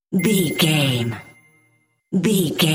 Bright Logo Bell
Sound Effects
Atonal
magical
mystical